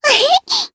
Rodina-Vox_Happy1_jp.wav